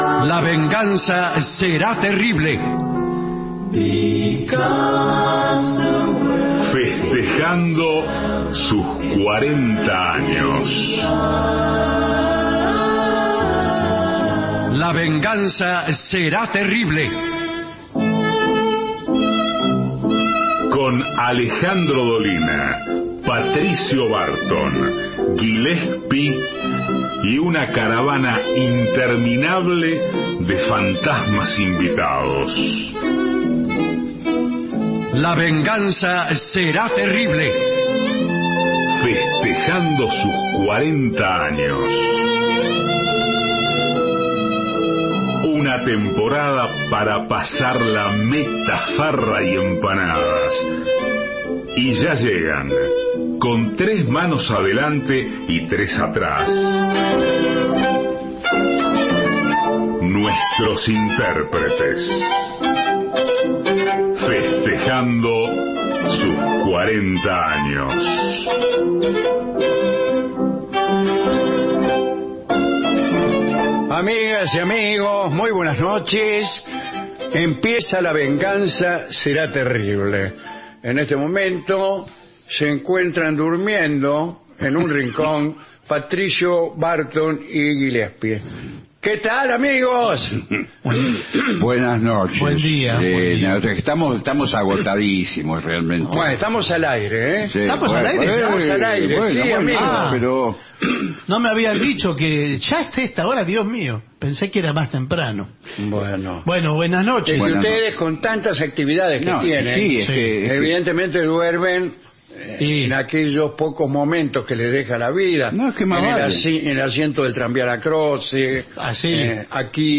La Venganza Será Terrible: todo el año festejando los 40 años Estudios AM 750 Alejandro Dolina, Patricio Barton, Gillespi Introducción • Entrada[0:01:20]( play 0:01:20) Segmento Inicial •